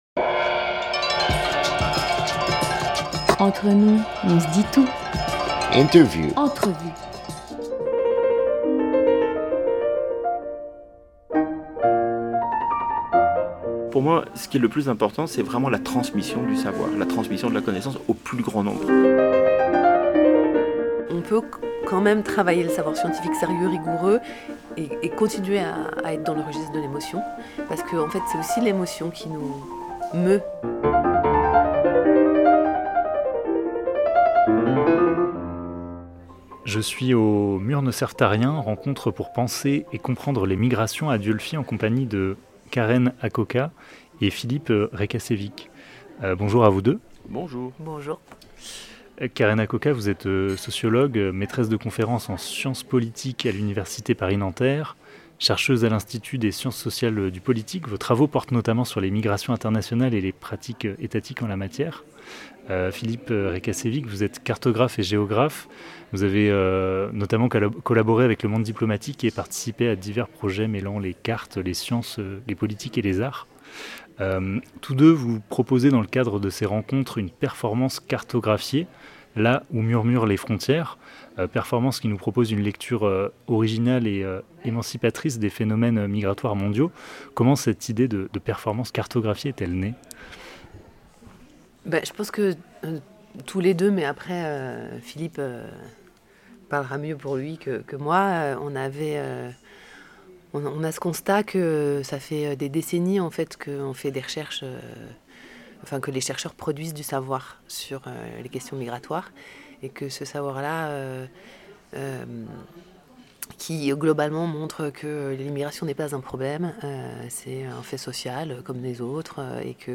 3 octobre 2024 11:20 | Interview